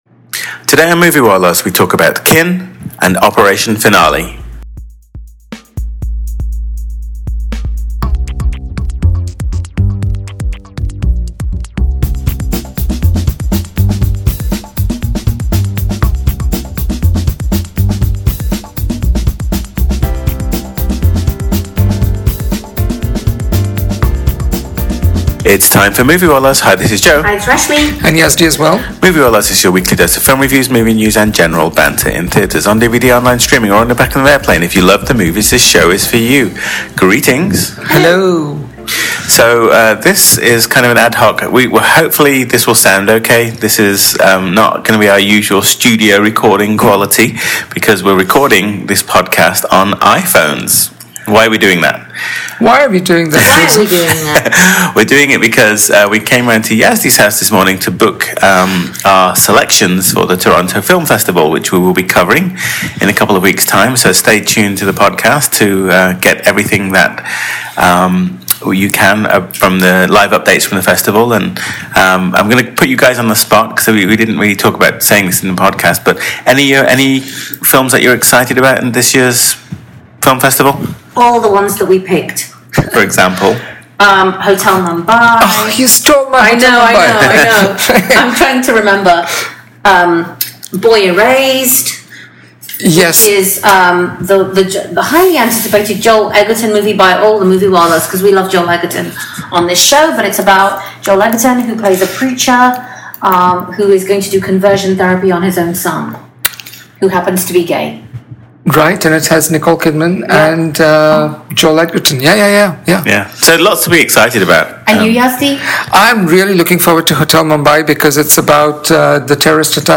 In this week’s Podcast we review: – Kin – Operation Finale Sincere apologies for the poor sound quality of this Podcast.